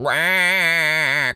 pgs/Assets/Audio/Animal_Impersonations/duck_quack_hurt_07.wav at master
duck_quack_hurt_07.wav